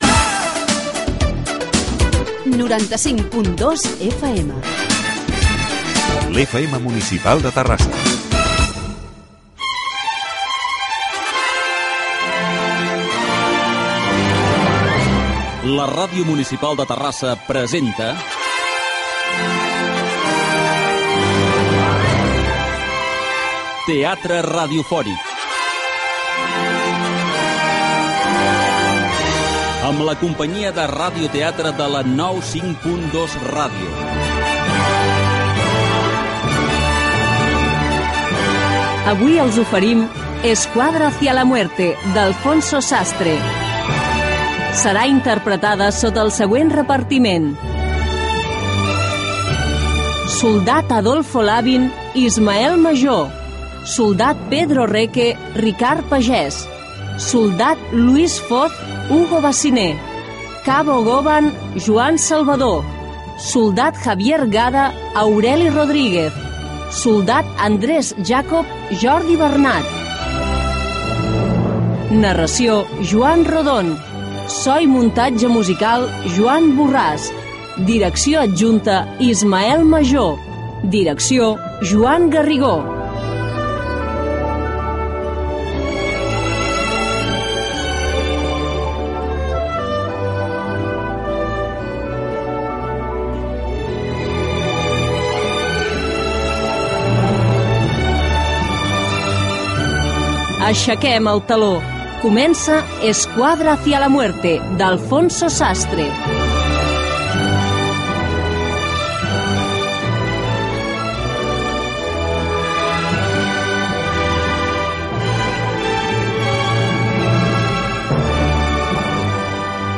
Teatre radiofònic
Indicatiu de l'emissora, careta del programa, noms del quadre escènic de la ràdio.
Ficció